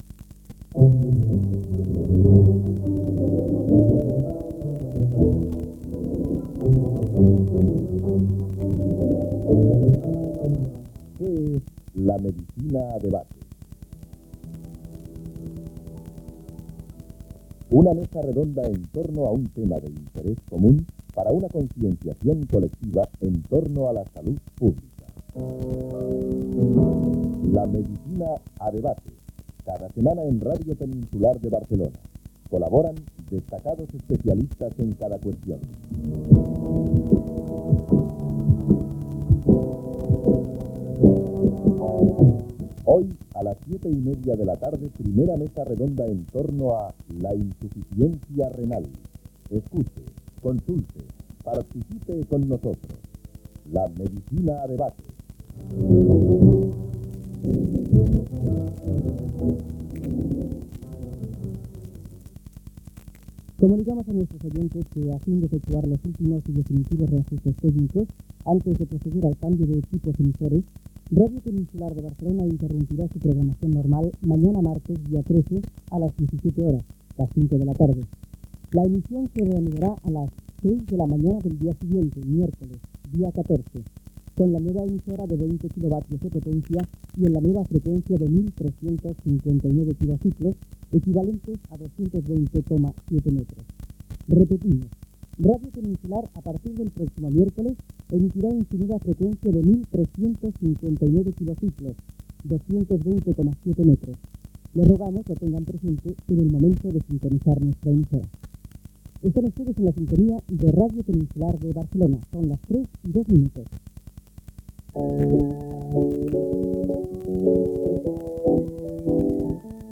Promoció "La medicina a debate", avís de la interrupció de l'emissió degut al canvi de transmissor i de freqüència, que serà 1359 KHz. Hora, sintonia, inici del programa
Entreteniment